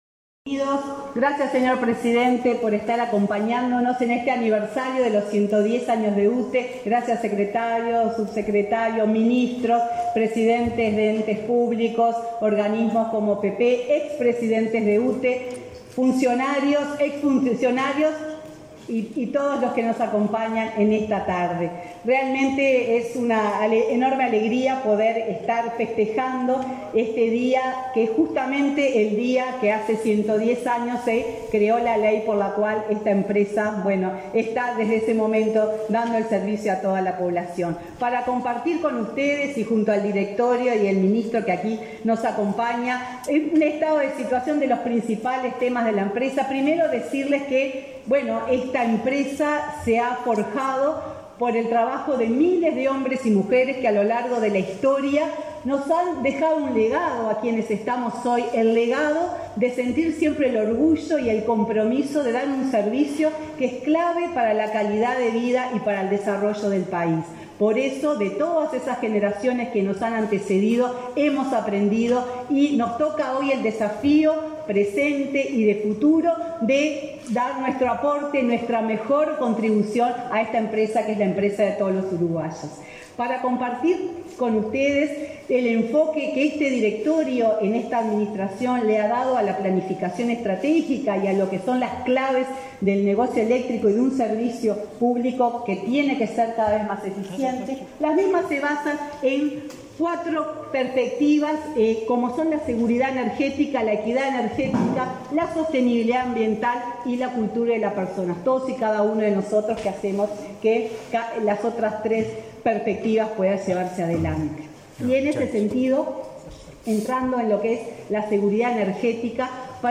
Conferencia de prensa por el aniversario 110 de UTE
Con la presencia del presidente de la República, Luis Lacalle Pou, se realizó, este 21 de octubre, el acto por los 110 años de UTE.
Participaron en el evento, la presidenta de la empresa estatal, Silvia Emaldi, y el ministro de Industria, Omar Paganini.